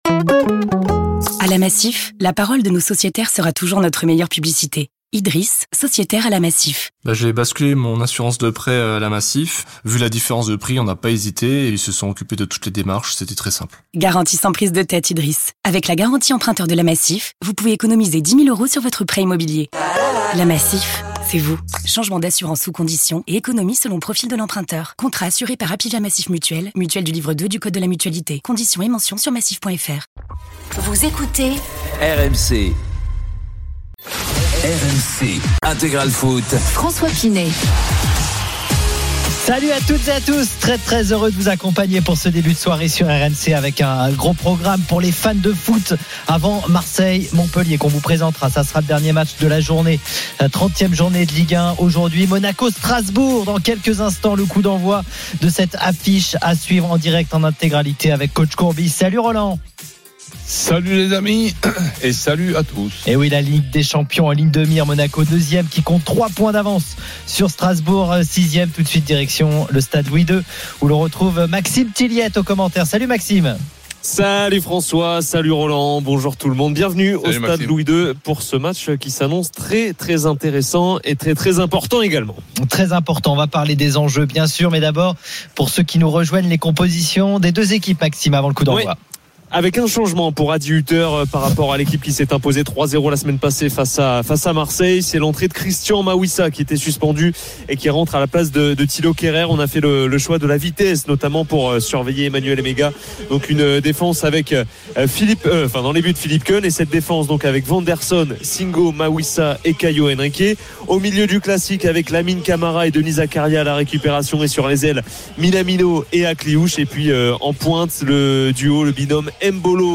Le rendez vous Ligue 2 de RMC. Huit matches par journée à suivre en direct et des acteurs du championnat (joueurs, entraîneurs, présidents) invités pendant deux heures.
RMC est une radio généraliste, essentiellement axée sur l'actualité et sur l'interactivité avec les auditeurs, dans un format 100% parlé, inédit en France.